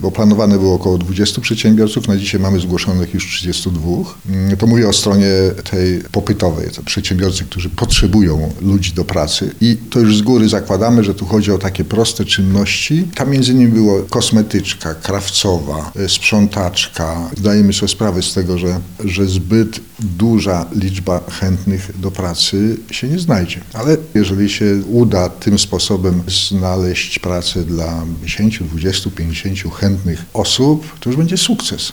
– Zapotrzebowanie na pracowników jest duże – mówi starosta lubelski Zdzisław Antoń (na zdj. z prawej).